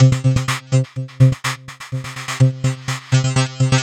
tx_perc_125_flangeit.wav